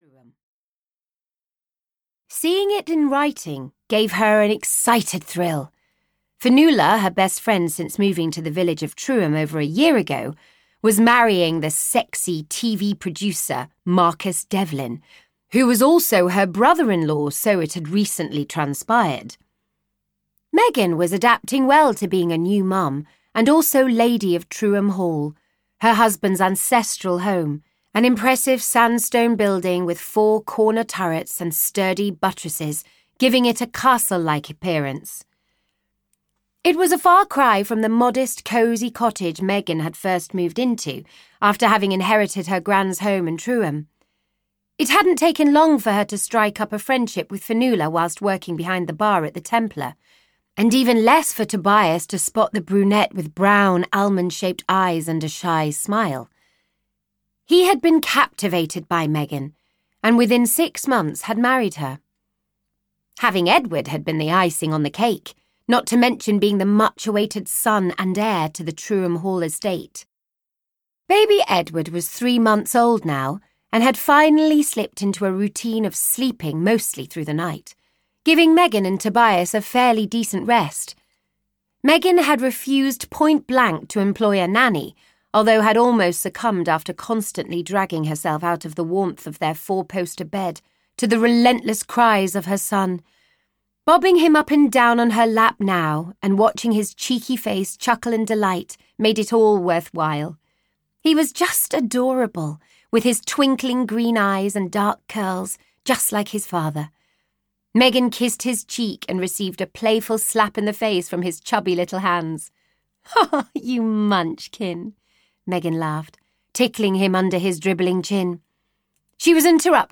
A Country Dilemma (EN) audiokniha
Ukázka z knihy